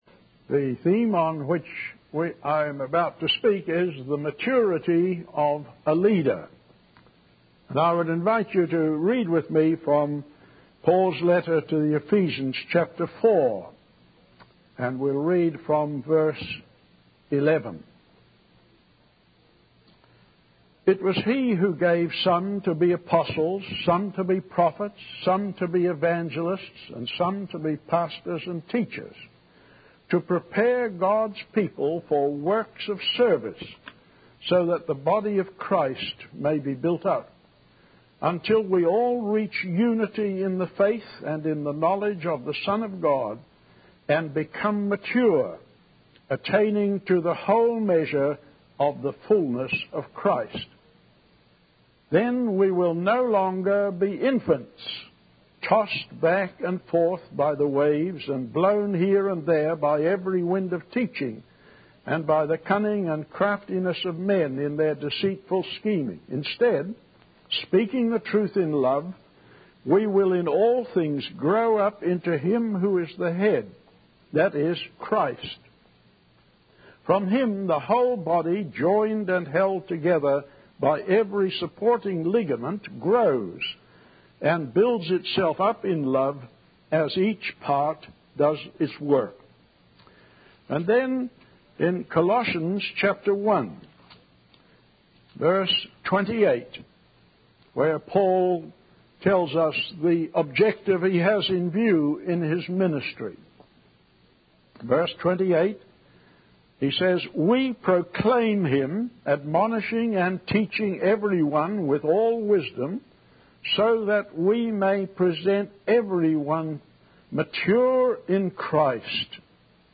In this sermon, the speaker emphasizes the importance of having someone behind us pouring on oil to keep us moving and generating power in our leadership positions. He encourages leaders to be kept at boiling point by the Holy Spirit and to serve the Master faithfully. The speaker then focuses on the qualities of a leader, particularly in the areas of speech, life, love, faith, and purity.